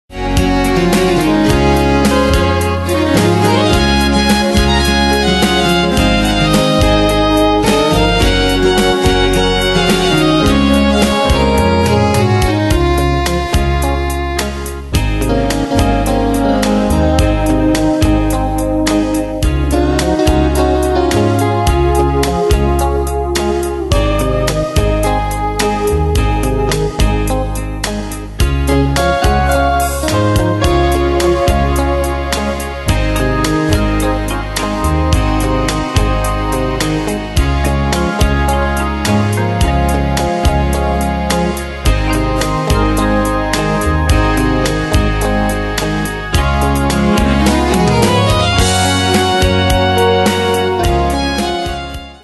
Style: Country Année/Year: 2001 Tempo: 108 Durée/Time: 3.18
Danse/Dance: Rhumba Cat Id.
Pro Backing Tracks